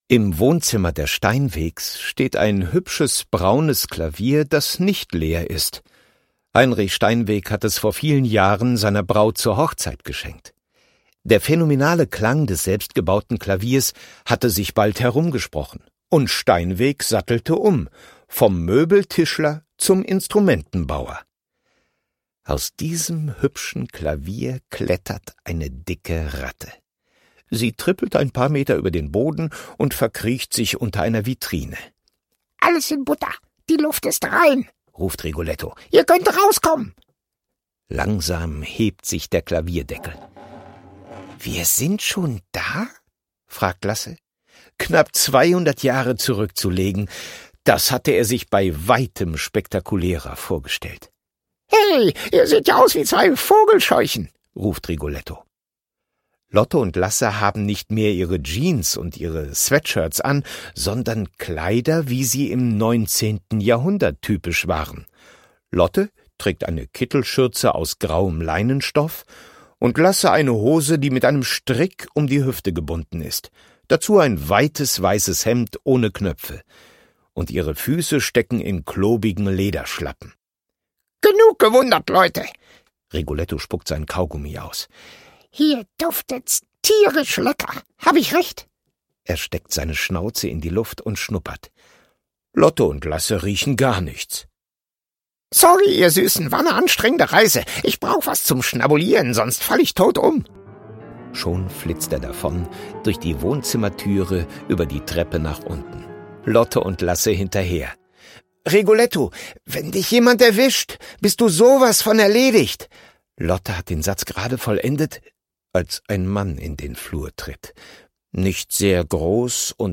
Hörbuch: Professor Dur und die Notendetektive 1.